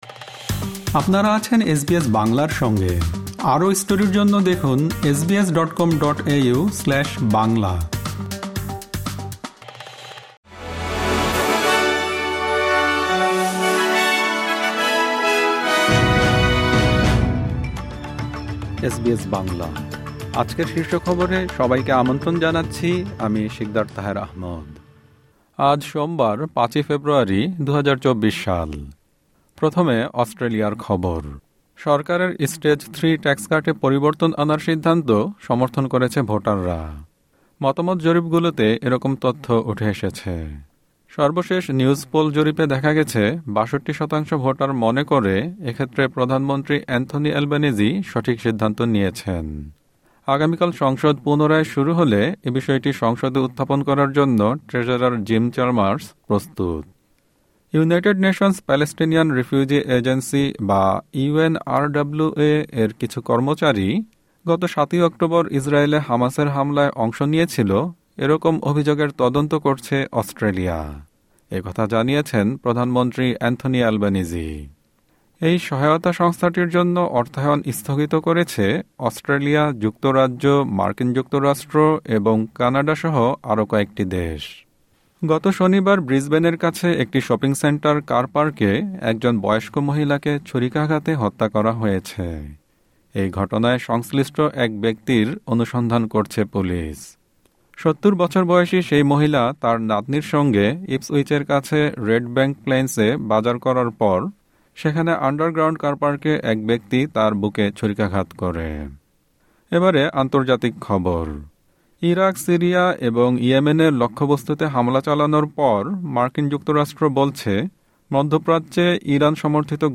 এসবিএস বাংলা শীর্ষ খবর: ৫ ফেব্রুয়ারি, ২০২৪